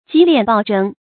急斂暴征 注音： ㄐㄧˊ ㄌㄧㄢˇ ㄅㄠˋ ㄓㄥ 讀音讀法： 意思解釋： 嚴急而苛猛的賦稅。